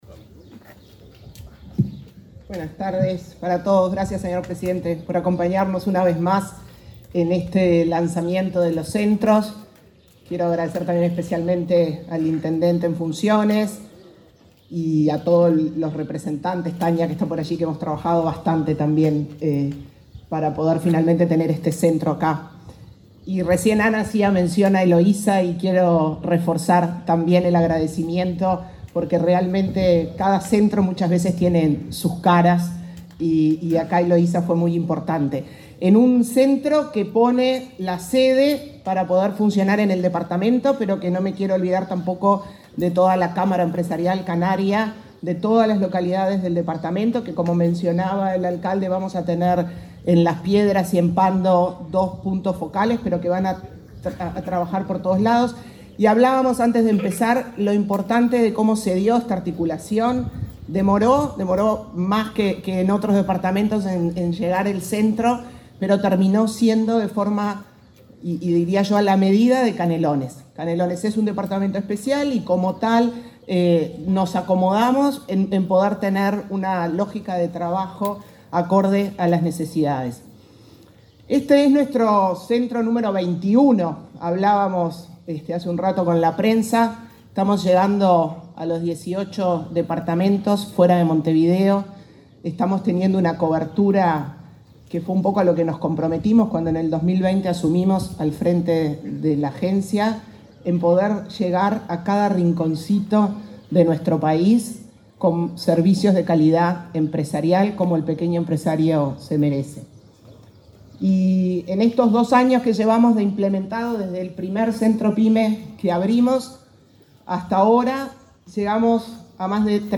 Palabras de la presidenta de ANDE, Carmen Sánchez
Palabras de la presidenta de ANDE, Carmen Sánchez 16/10/2023 Compartir Facebook X Copiar enlace WhatsApp LinkedIn Este lunes 16, la presidenta de la Agencia Nacional de Desarrollo (ANDE), Carmen Sánchez, participó en la inauguración de un centro pyme en la localidad de Santa Lucía, en el departamento de Canelones.